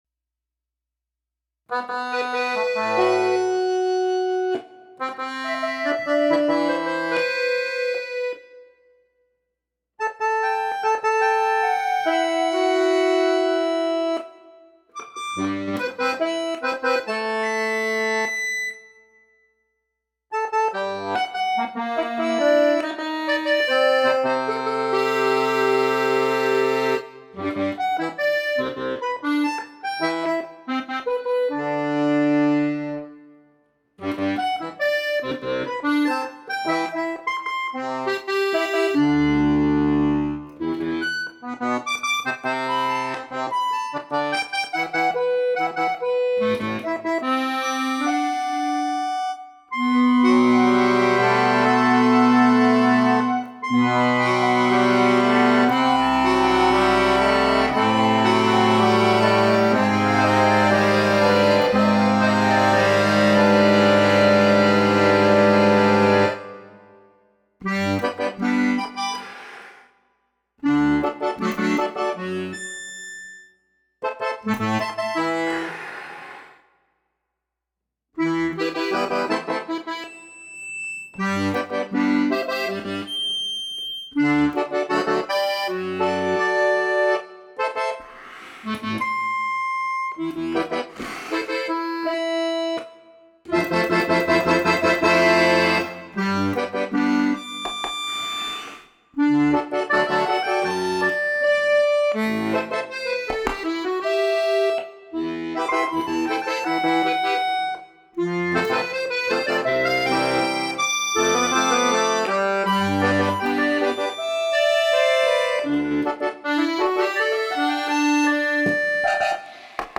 Contemporary, classical, folk-influenced